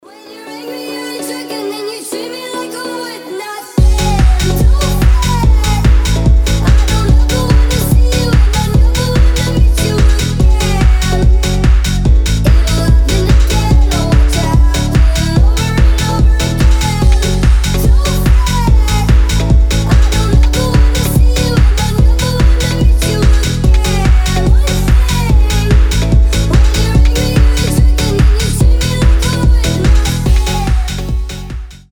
быстрые
house
динамичные
ремиксы
piano house